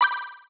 menu-edit-click.wav